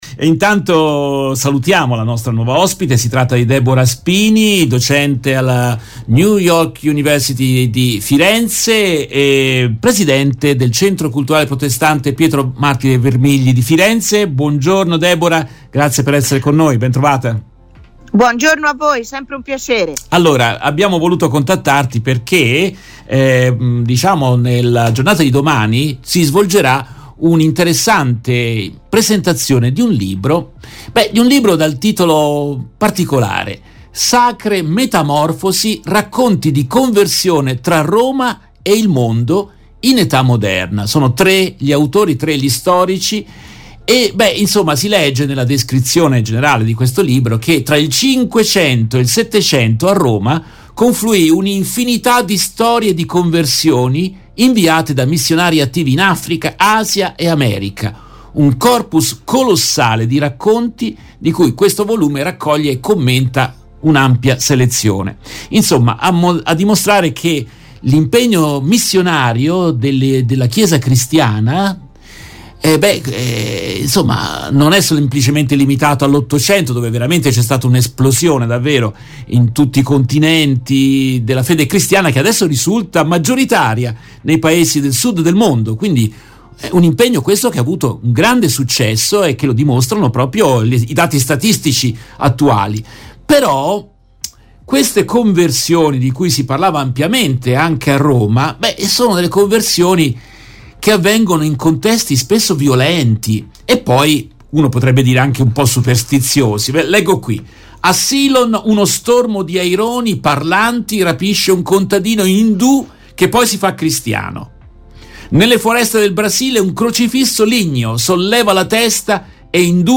hanno intervistato